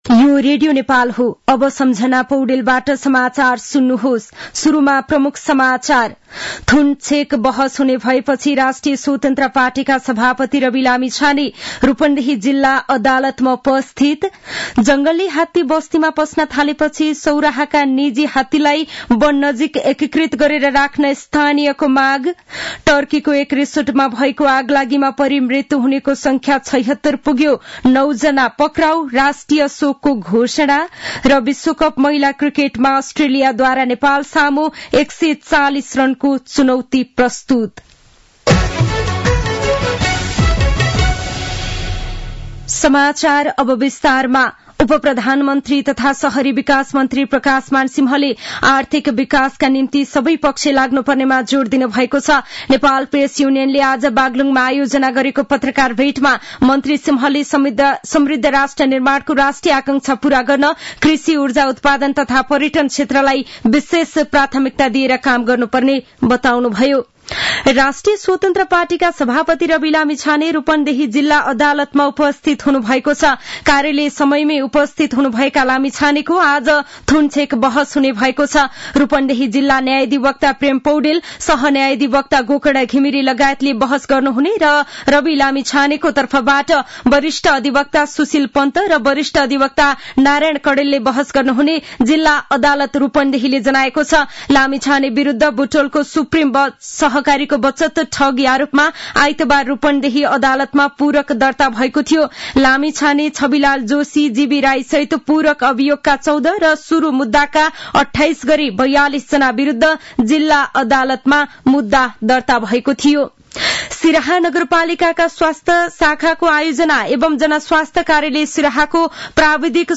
दिउँसो ३ बजेको नेपाली समाचार : १० माघ , २०८१
3-pm-news-1.mp3